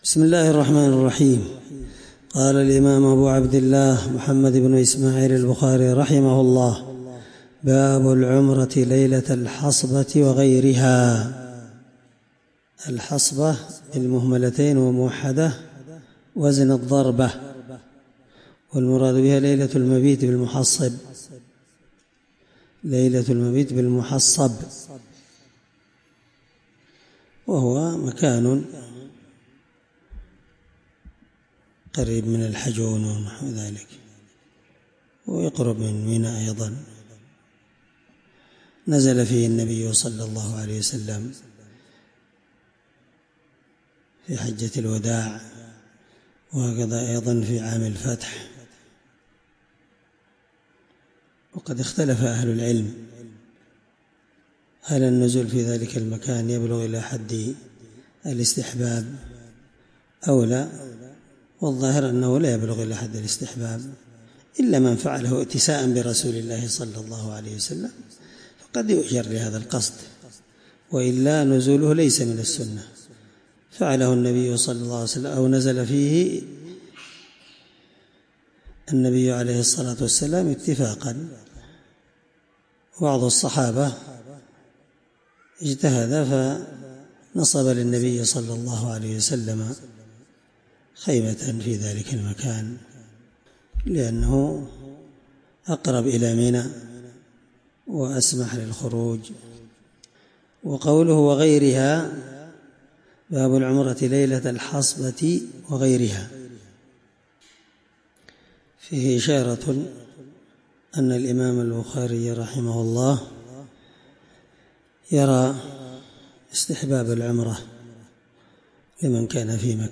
الدرس 4من شرح كتاب العمرة حديث رقم(1783)من صحيح البخاري